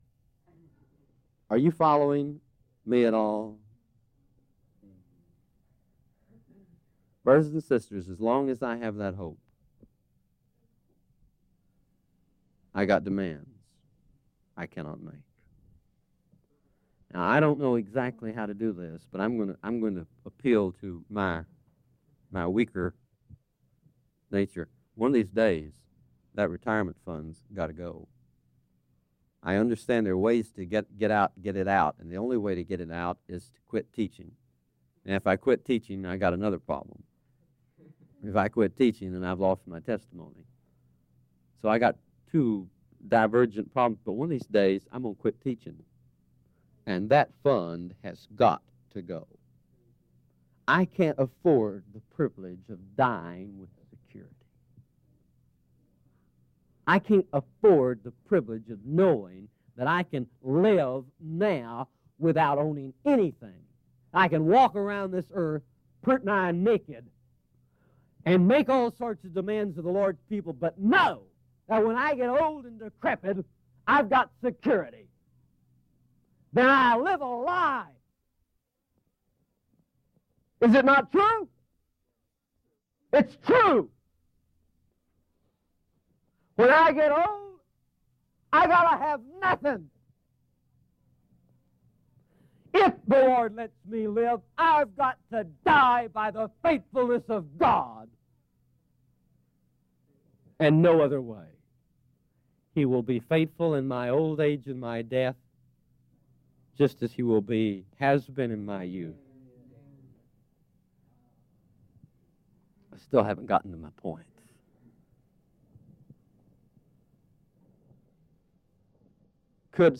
speaks with urgency about the true nature of the kingdom of God—not as something outward, but as the deep, personal gaining of Jesus Christ Himself.
Messages to the Church in Isla Vista, CA